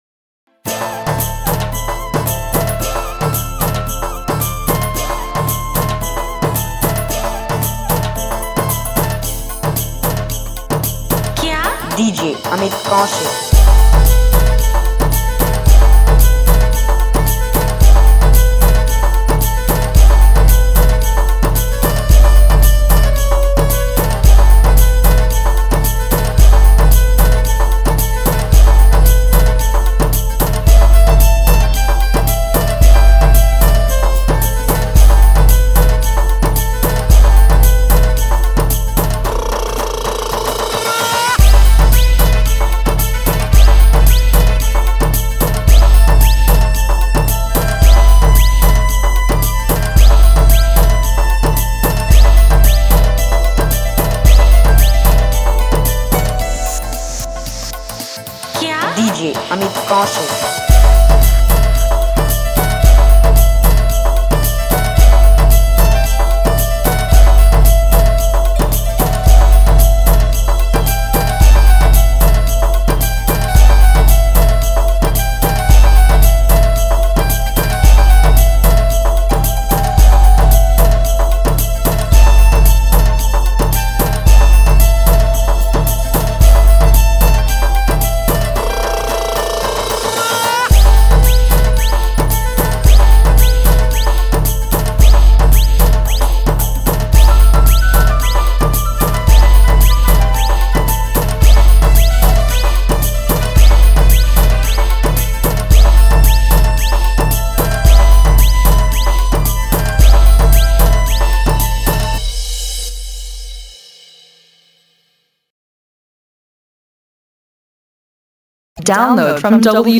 New Dj Song